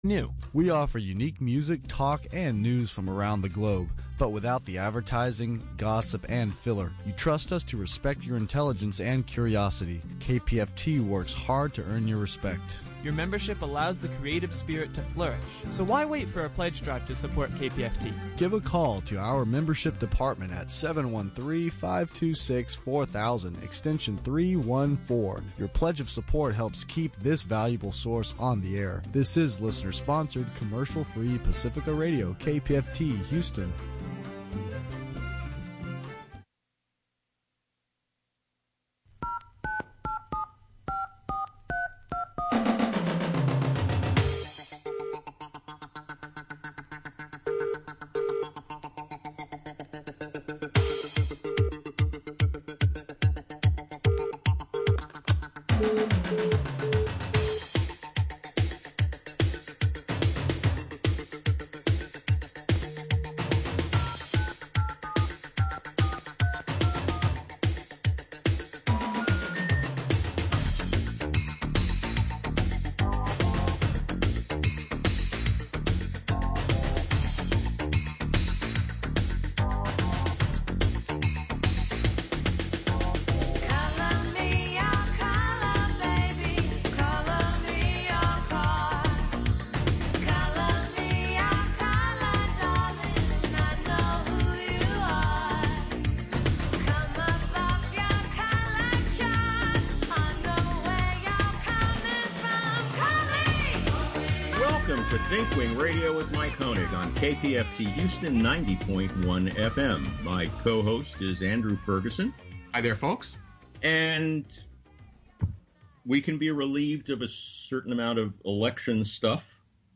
This program was recorded early in the morning on SUNDAY, November 29. Due to Covid-19, shows are being prerecorded beginning March 13, 2020 until further notice.